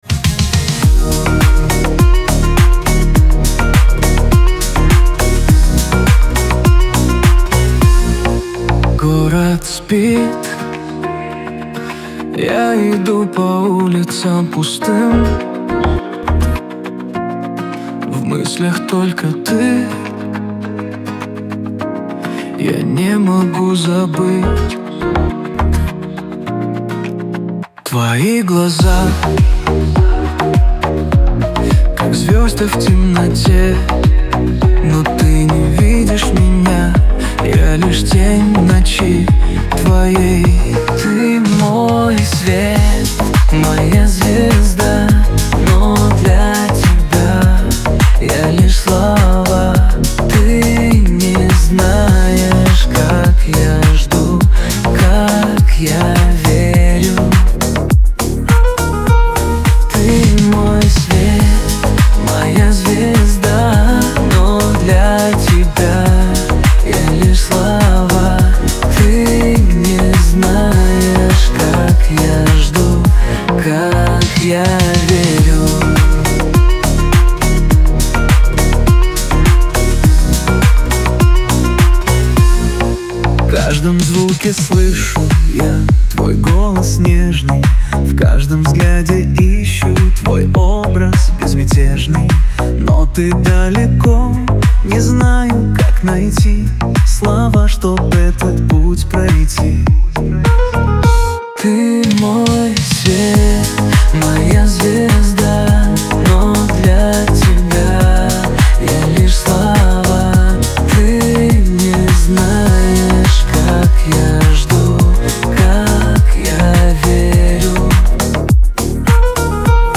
Лирика
pop